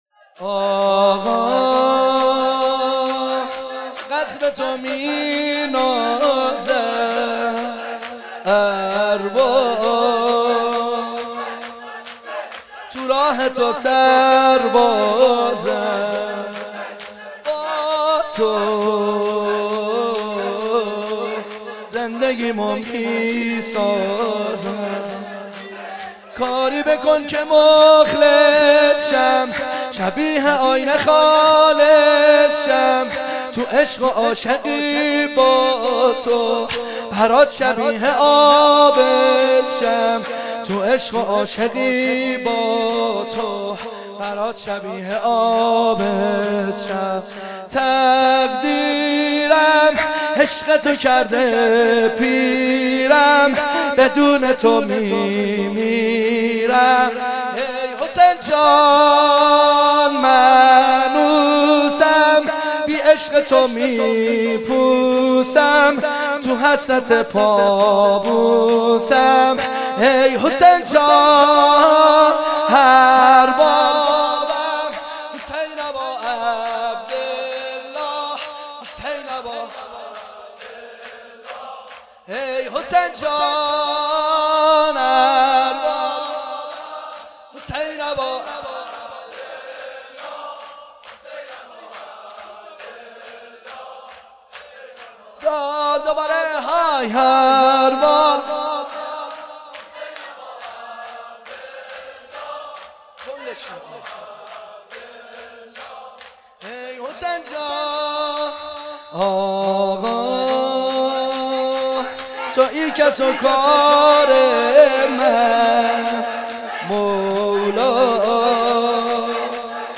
مداحی شب دهم محرم ۹۶
مراســم عـــزادارى شــب دهم محرم
مراسم شب دهم محرم
شور۲